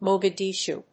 /ˌmogʌˈdiˌʃu(米国英語), ˌməʊgʌˈdi:ˌʃu:(英国英語)/